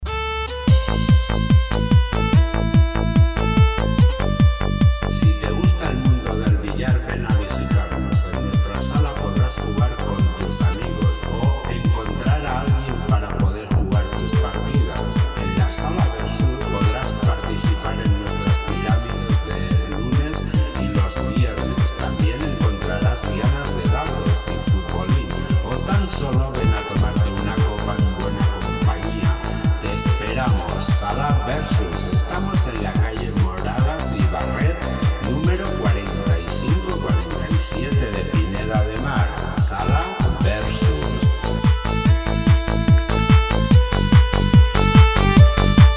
CUÑA PUBLICITARIA VERSUS_mezcla.mp3 (1,1 MB)